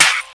WAR TIME ALL THE TIME SNARE (MY FAV).wav